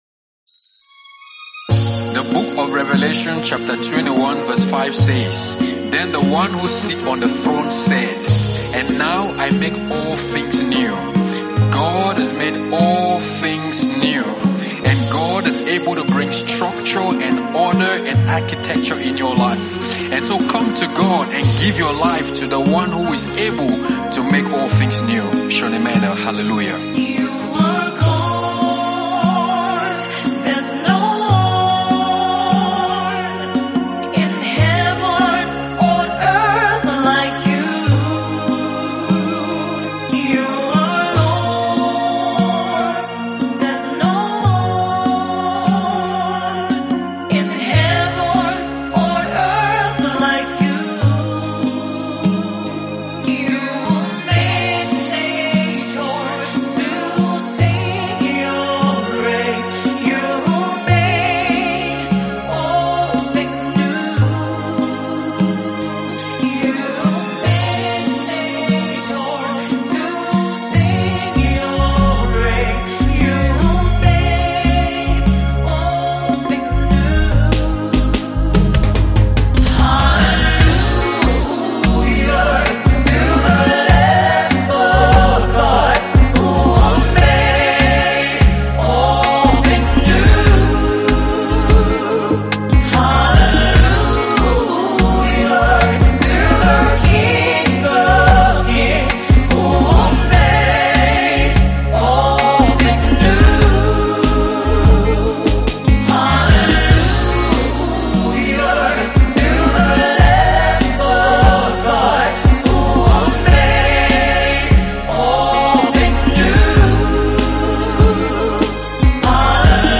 Christian contemporary worship song